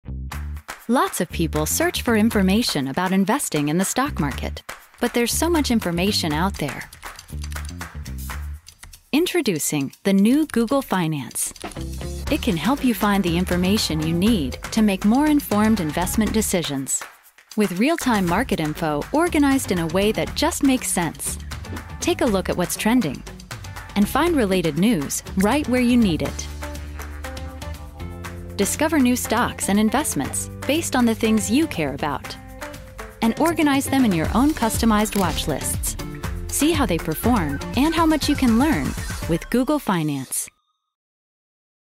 standard us
commercial
professional home studio